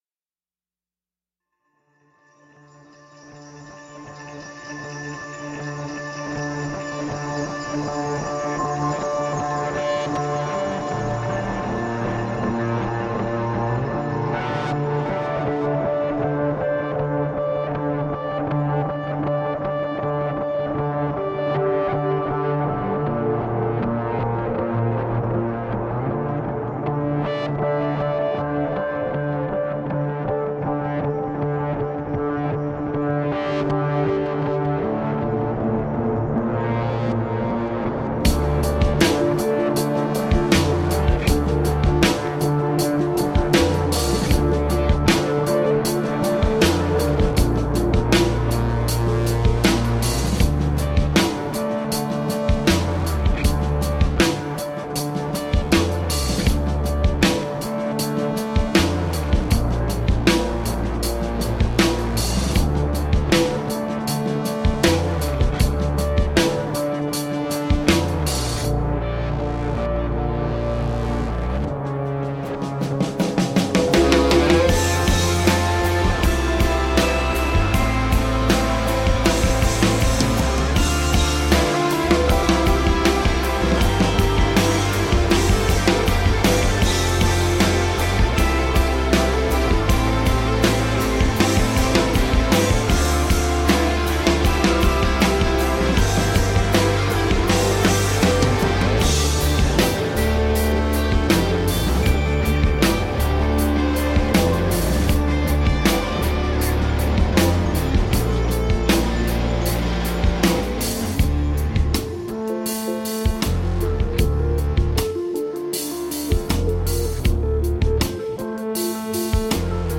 Moody blend of electronica and rock.
A compelling new blend of rock and electronica.
Tagged as: Electro Rock, Electronica